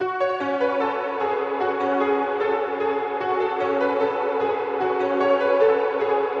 快速钢琴1
Tag: 150 bpm Trap Loops Piano Loops 1.08 MB wav Key : Unknown